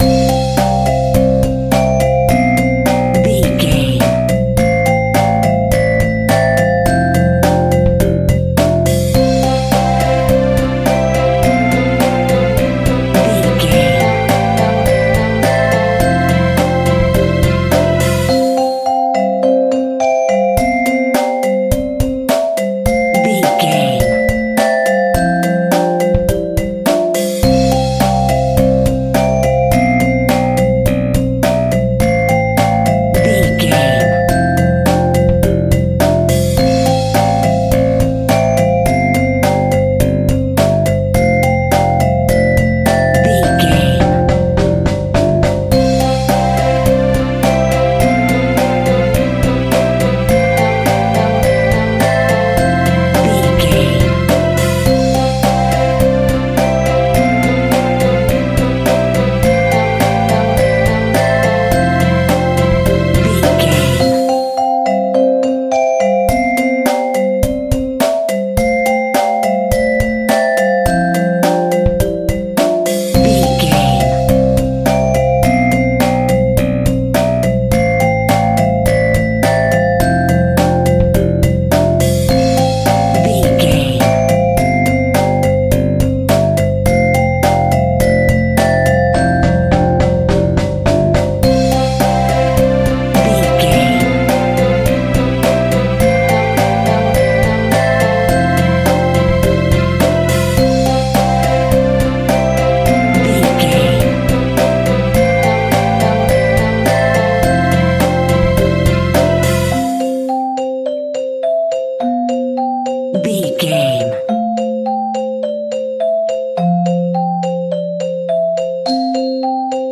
Kids Upbeat Music.
Uplifting
Ionian/Major
Acoustic Piano
drums
bass guitar
synths
marima
vibraphone
xylophone